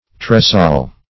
Tresayle \Tres"ayle`\ (tr[e^]s"[=a]l`), n. [F. trisa["i]eul,